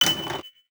File File history File usage Metadata DTL-SFX_CG_TEMPLATE_FLASHES.ogg  (Ogg Vorbis sound file, length 0.7 s, 469 kbps) This file is an audio rip from a(n) Nintendo DS game.
Reason: Known bad rip; is too high-pitched.
DTL-SFX_CG_TEMPLATE_FLASHES.ogg